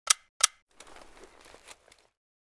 92fs_inspect_jammed.ogg